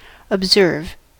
observe: Wikimedia Commons US English Pronunciations
En-us-observe.WAV